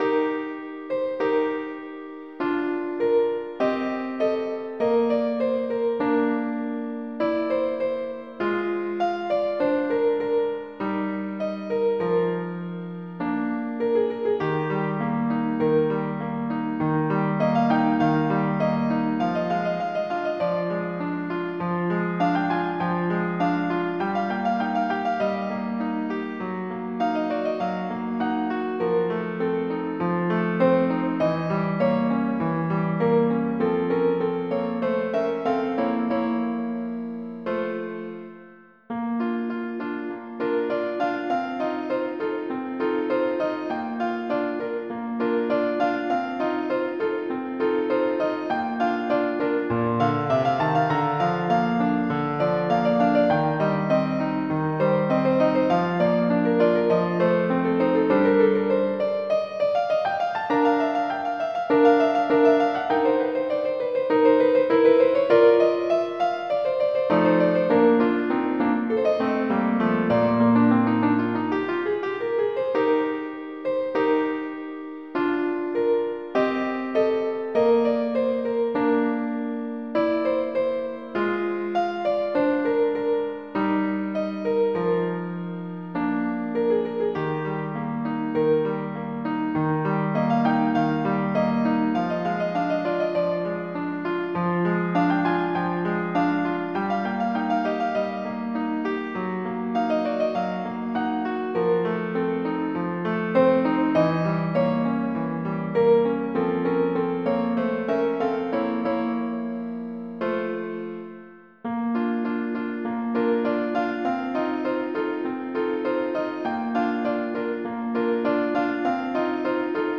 MIDI Music File
"Adagio"
General MIDI